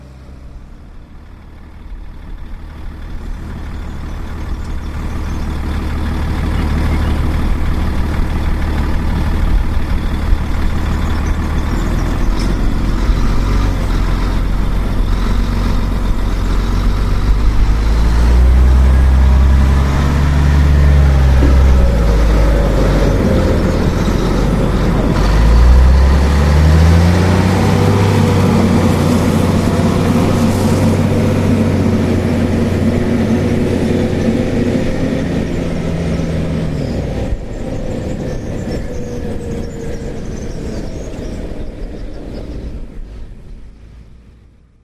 Station 2: Kampf Geräusch: an- und abschwellendes Motorengedröhn. Emotionslose Maschinen.